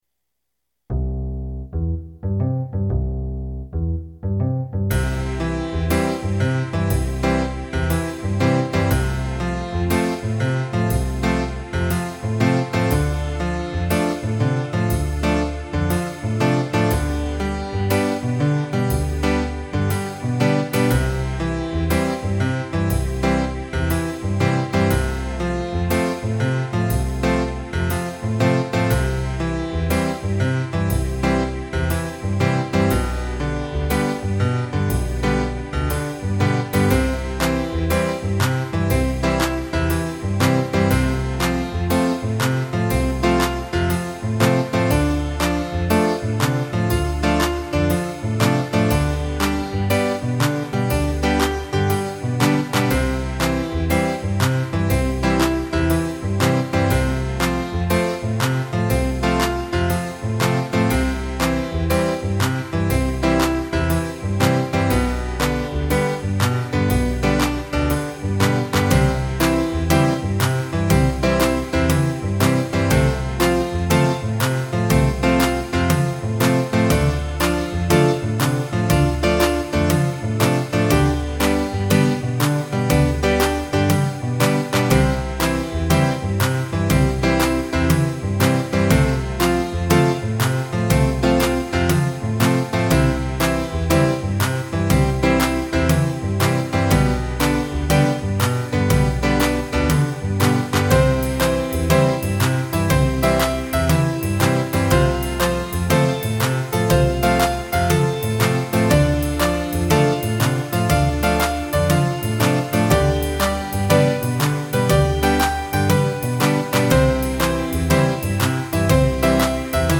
computer with steinberg pc-midi-3 serial midi-interface roland sound canvas sc-55 midi-expander